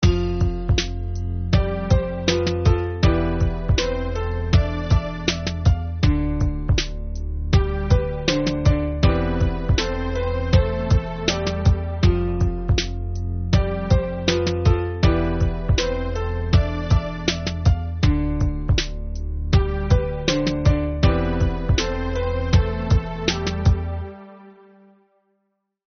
Минусовки (Hip-Hop)
Агрессивные: